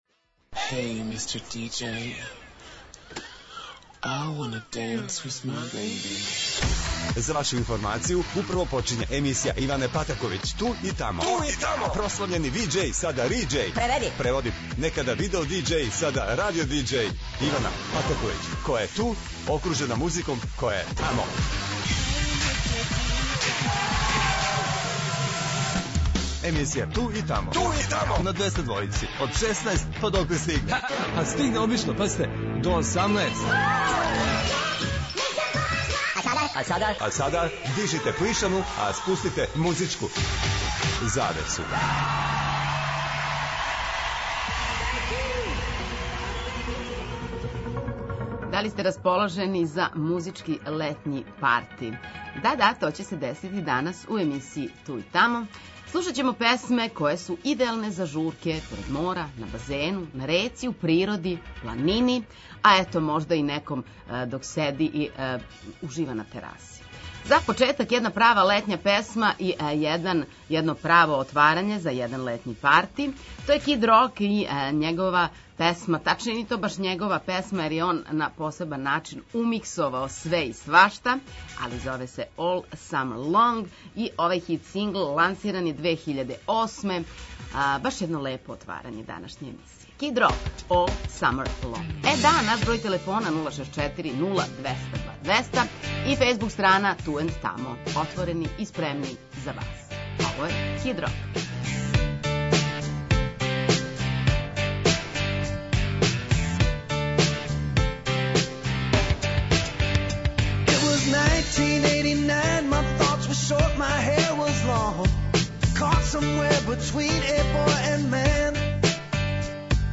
Да ли сте расположени за музички летњи парти? У емисији 'Ту и тамо' ове суботе на програму су песме идеалне за журке поред мора,на базену,на реци,у природи и планини...